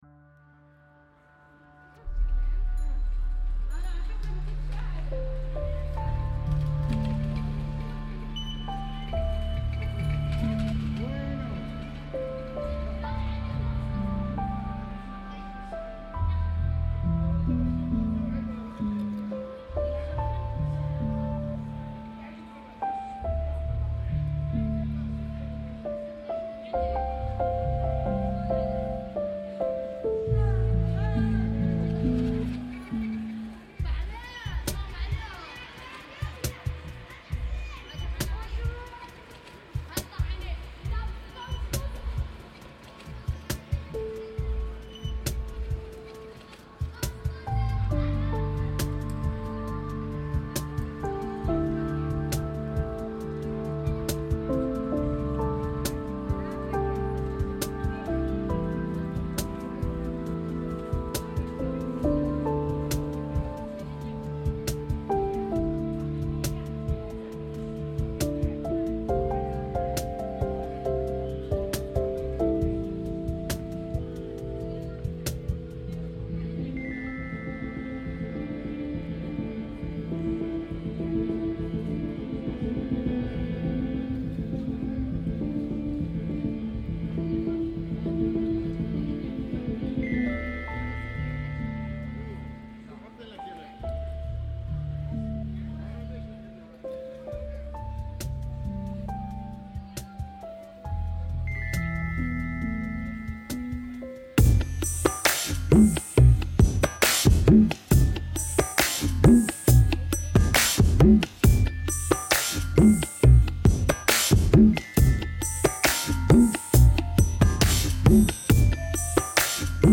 Bethlehem soundscape reimagined